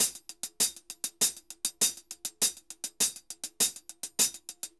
HH_Salsa 100_1.wav